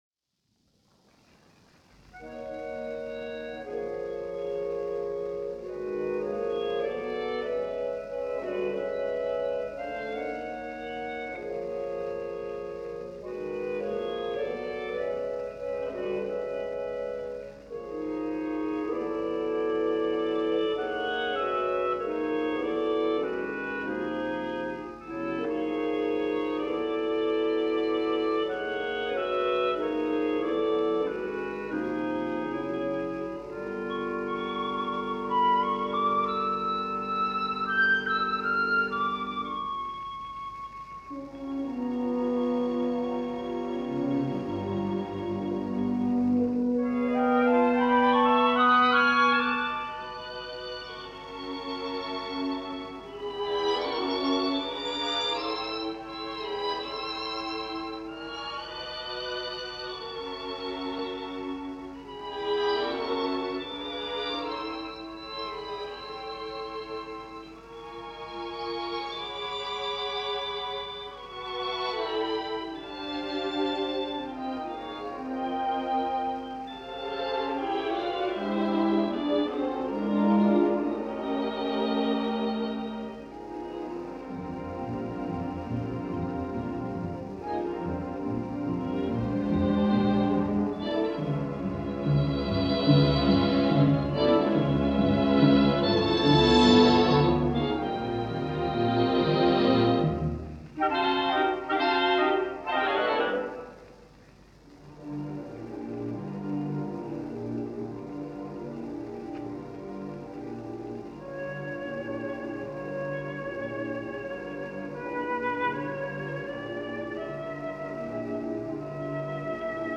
constant drive for a richer sound palette.
tonal color